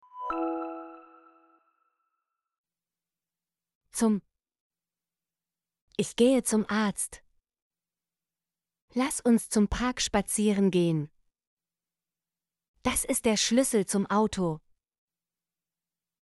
zum - Example Sentences & Pronunciation, German Frequency List